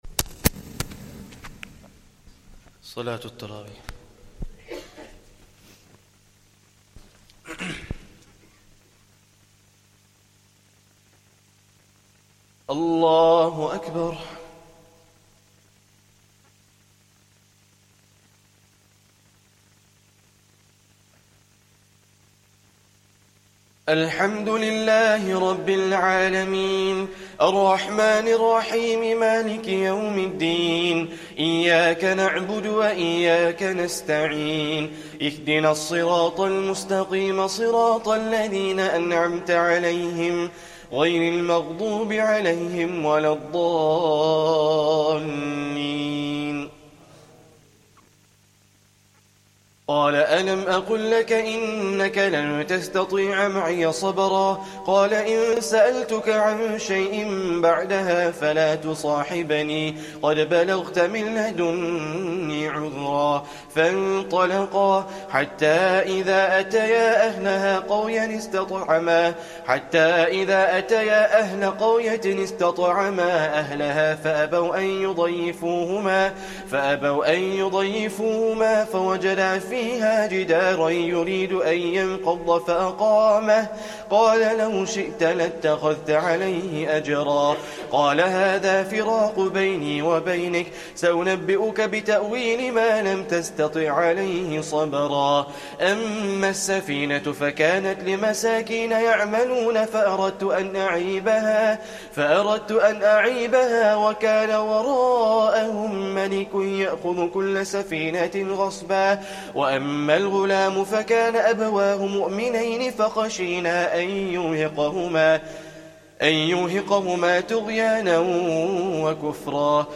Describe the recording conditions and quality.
Taraweeh Prayer 14th Ramadhan